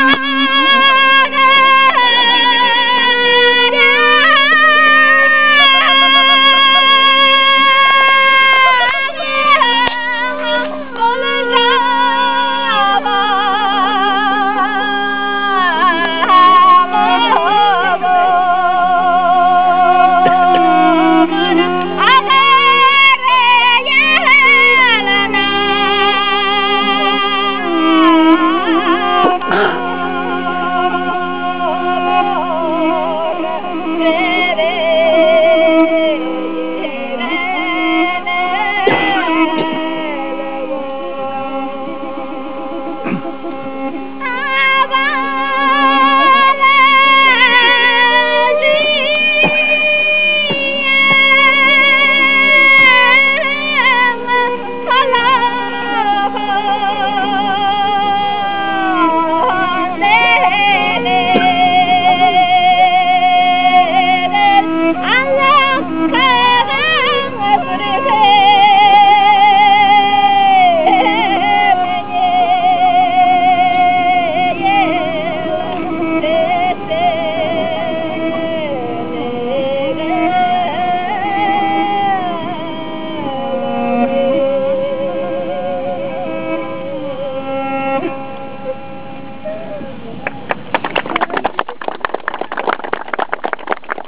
We were treated to a nice outdoor concert.
BuddhistTempDatsanMusic1.WAV